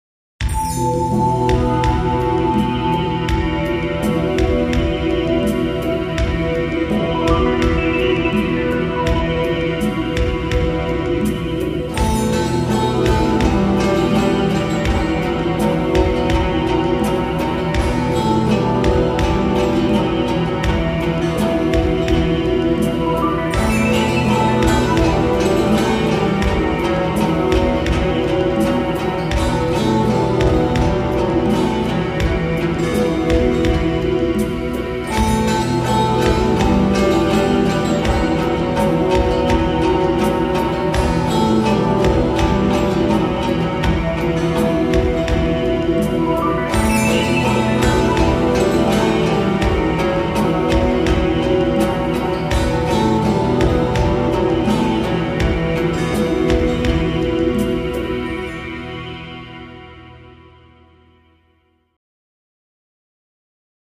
Мансийская мелодия – Совыр Соим – Заячий ручей (нарсьюх)